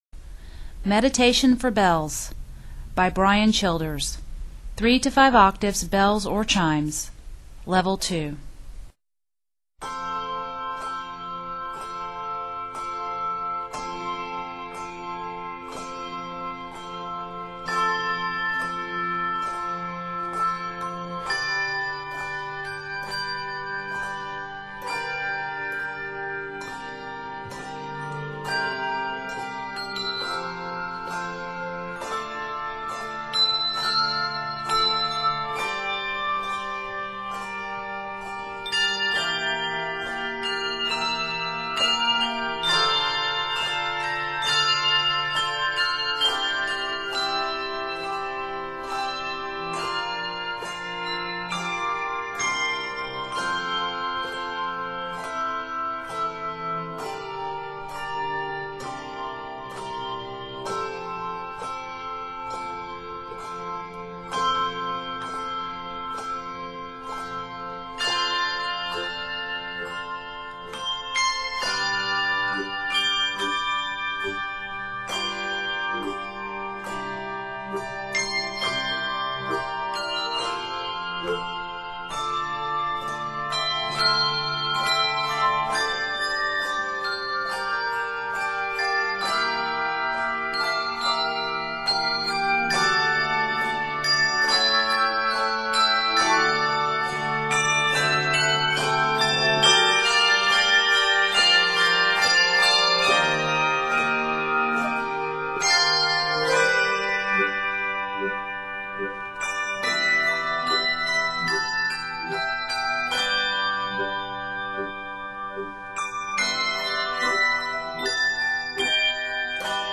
is primarily set in F Major, G Major and C Major